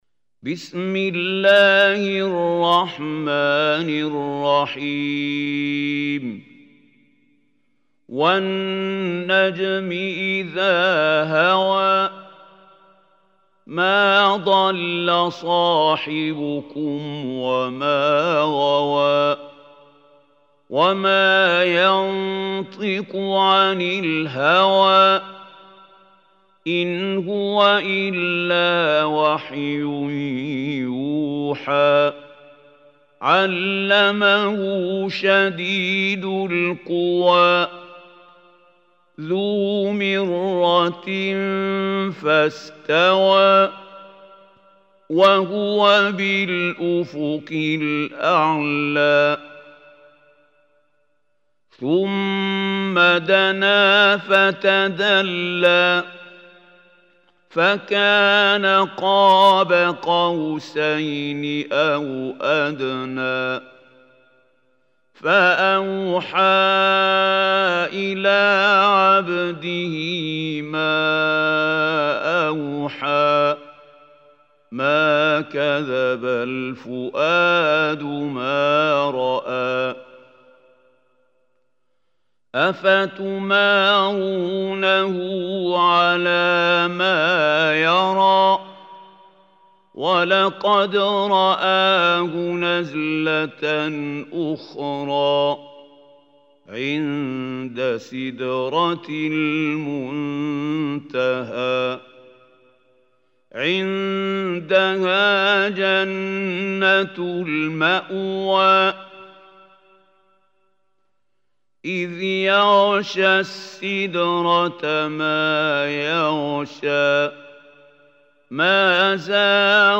Surah An-Najm Recitation by Mahmoud Khalil Hussary
Surah Najm is 53 surah of Holy Quran. Listen or play online mp3 tilawat / recitation in the beautiful voice of Sheikh Mahmoud Khali Al Hussary.